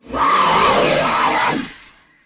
Song: Zombie Growl